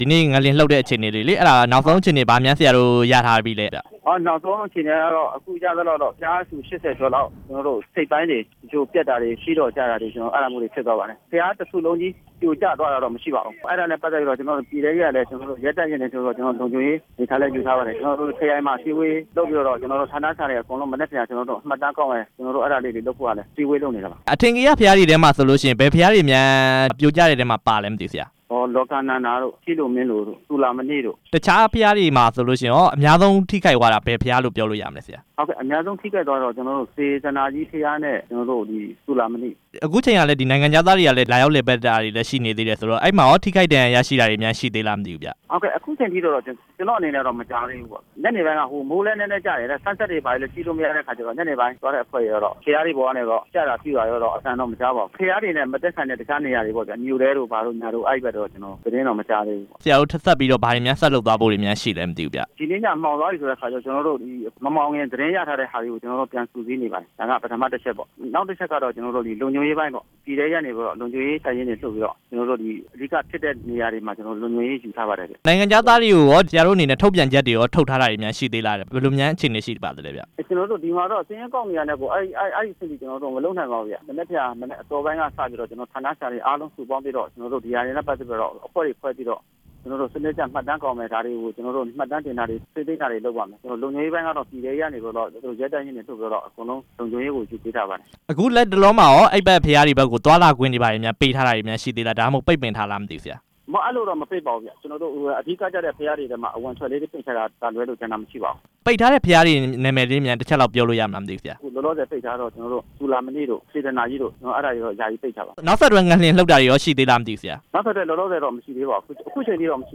ငလျင်ကြောင့် ပုဂံဘုရားစေတီတွေ ပျက်စီးမှုအခြေအနေ မေးမြန်းချက်